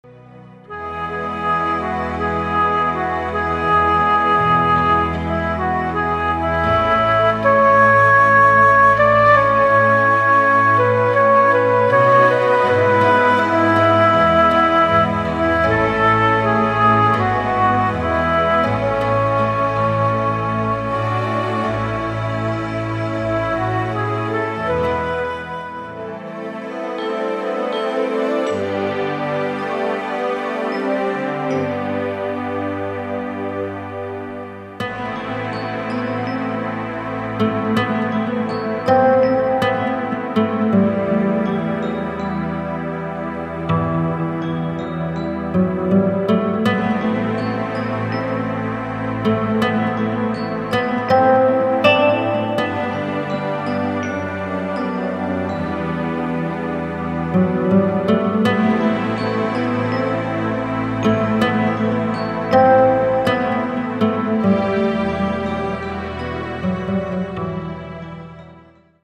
Mélodies populaires pour l’esprit